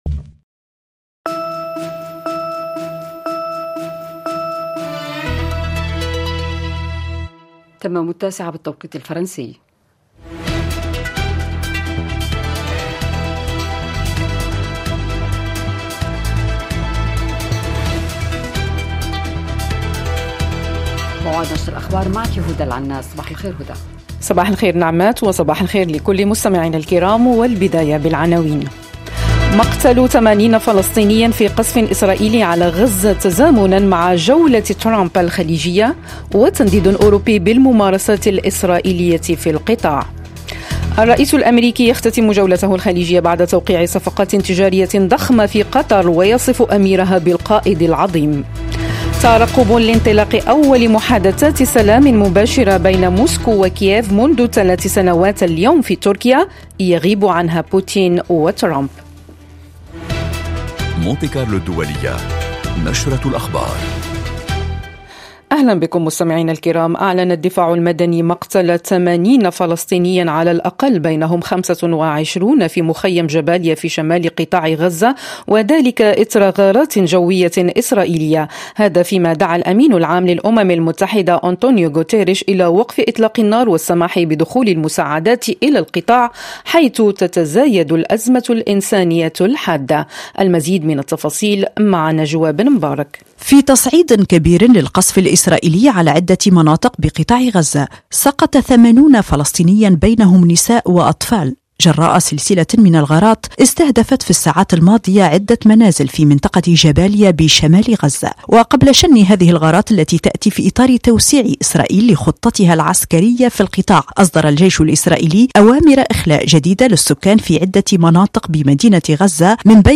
برامج مونت كارلو الدولية من تقديم صحفيين ومذيعين متخصصين وتعتمد في أغلبها على التواصل اليومي مع المستمع من خلال ملفات صحية واجتماعية ذات صلة بالحياة اليومية تهم المرأة والشباب والعائلة، كما أنها تشكل نقطة التقاء الشرق بالغرب والعالم العربي بفرنسا بفضل برامج ثقافية وموسيقية غنية.